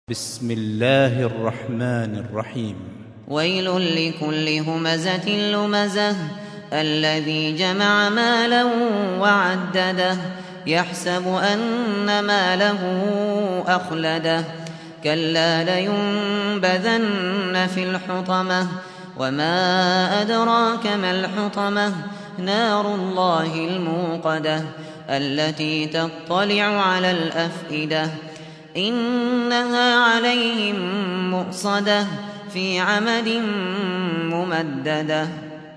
سُورَةُ الهُمَزَةِ بصوت الشيخ ابو بكر الشاطري